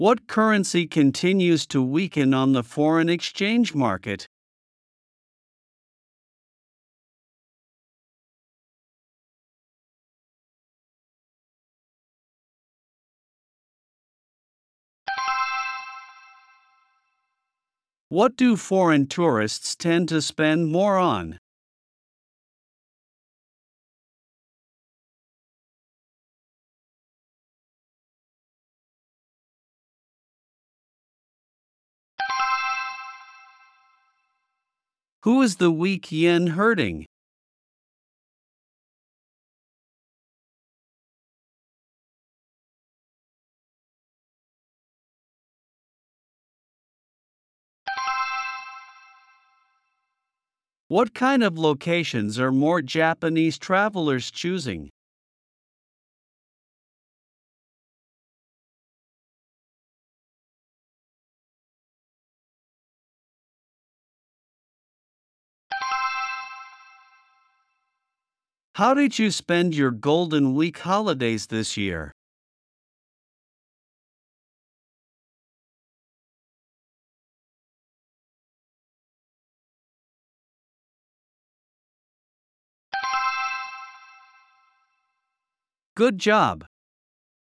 プレイヤーを再生すると英語で5つの質問が1問ずつ流れ、10秒のポーズ（無音部分）があります。
10秒後に流れる電子音が終了の合図です。
【B1レベル：10秒スピーチｘ5問】